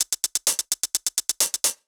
Index of /musicradar/ultimate-hihat-samples/128bpm
UHH_ElectroHatA_128-03.wav